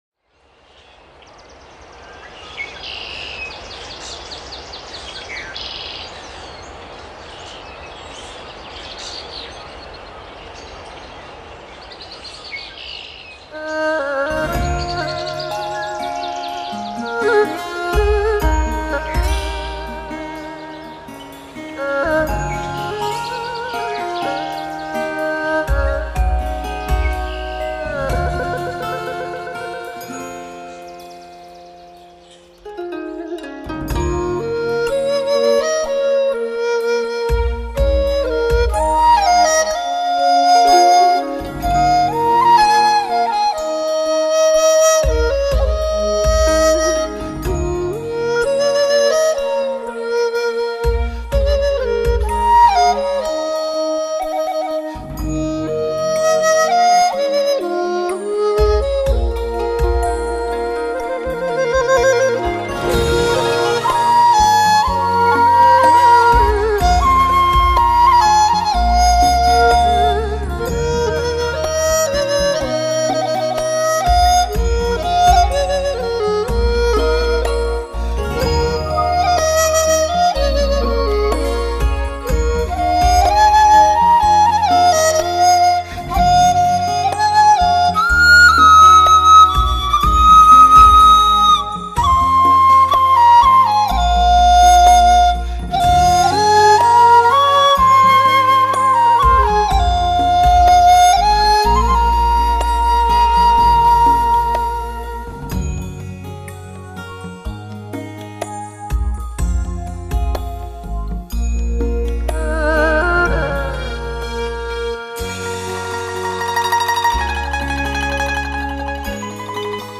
采用震撼性的DTS-ES6。1环绕声编码技术
古筝
二胡